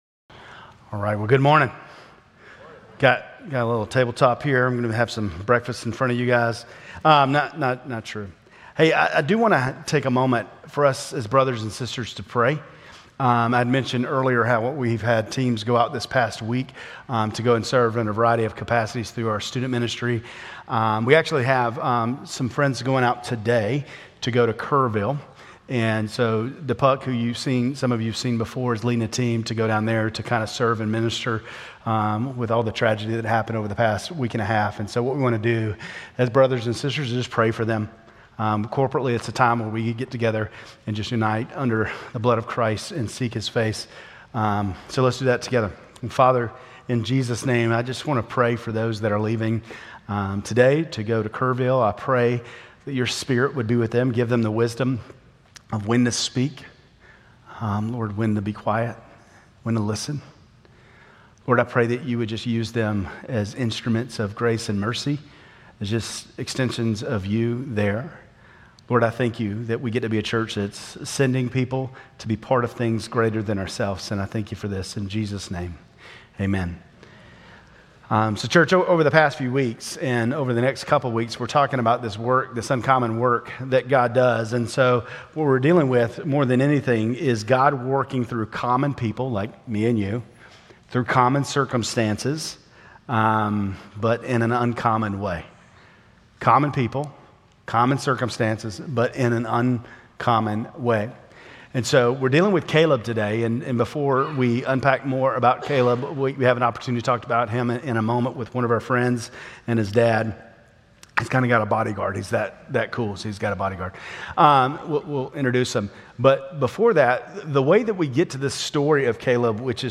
Grace Community Church Lindale Campus Sermons 7_13 Lindale Campus Jul 14 2025 | 00:29:30 1x 00:00 / 00:29:30 Subscribe Share RSS Feed Share Link Embed